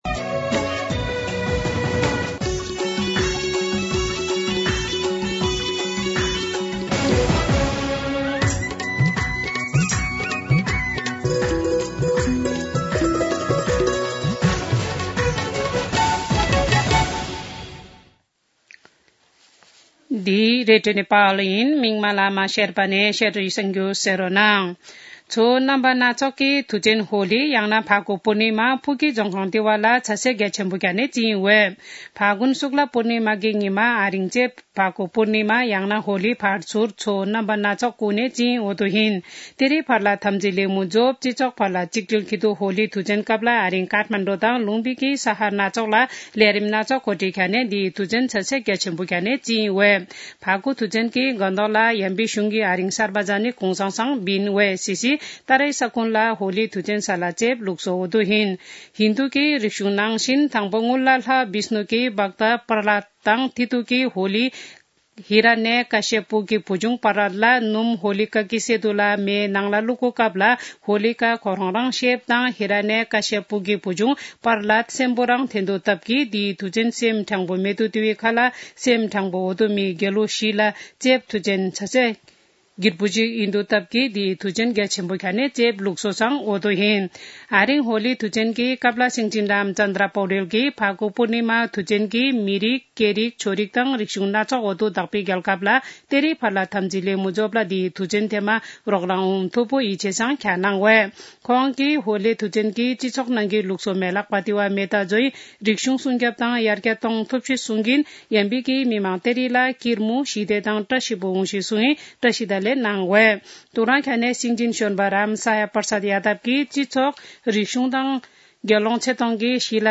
शेर्पा भाषाको समाचार : ३० फागुन , २०८१
Sherpa-news-11-29-.mp3